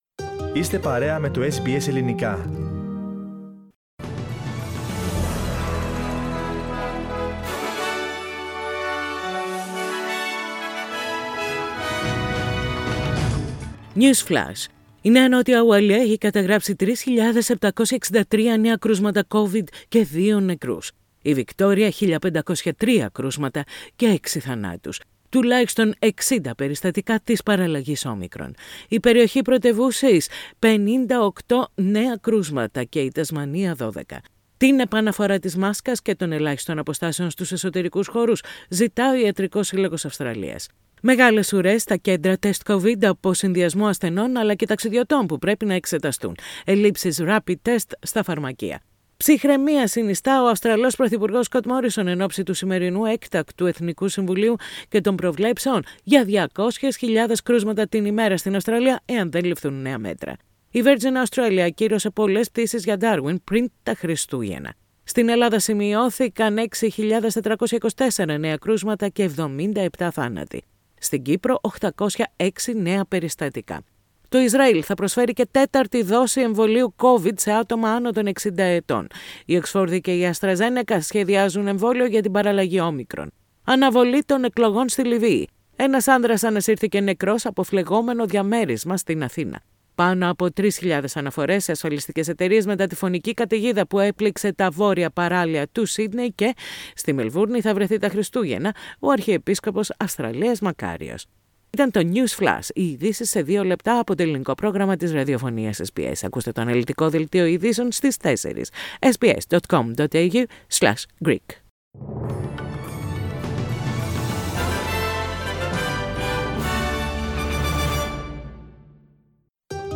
News Flash in Greek. Source: SBS Radio